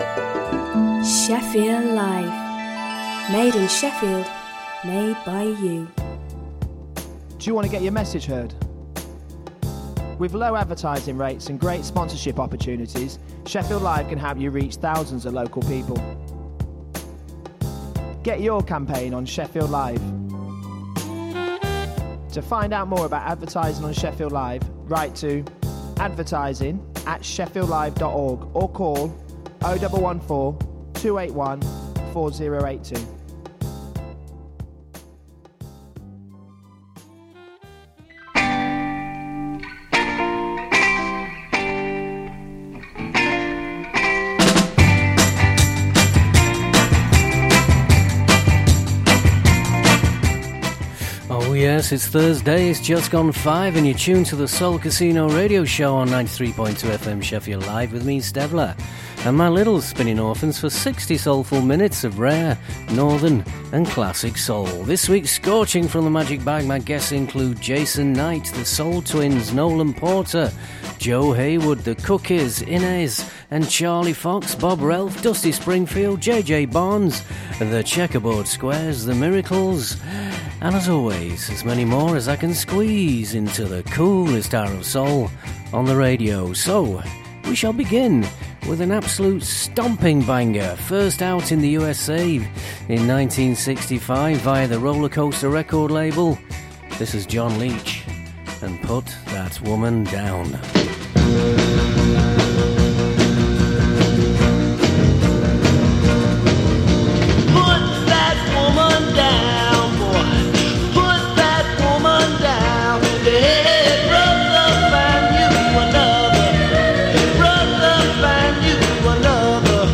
Sixty soulful minutes of rare, northen and classic soul.